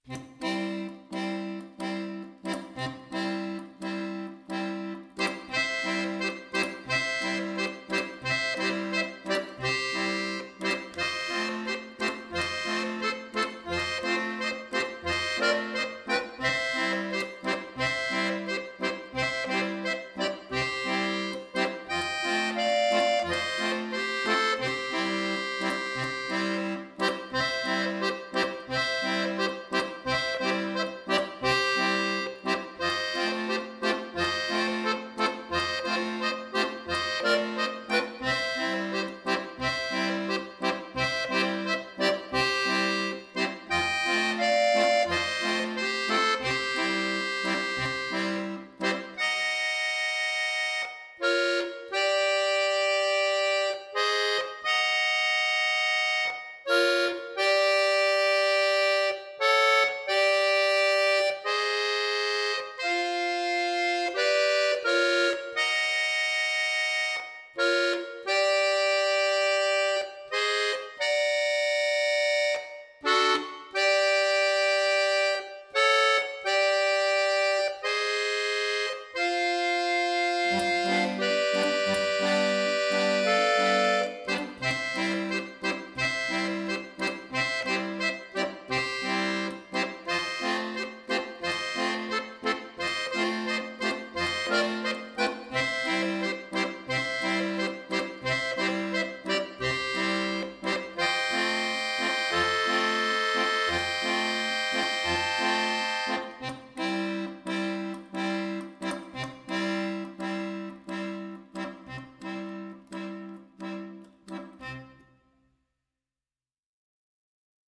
accordion Duration